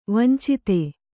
♪ vancite